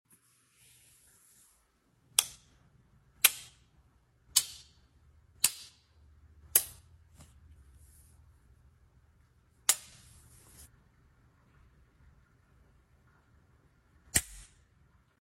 Freddy Fasbear Megabot Click ASMR sound effects free download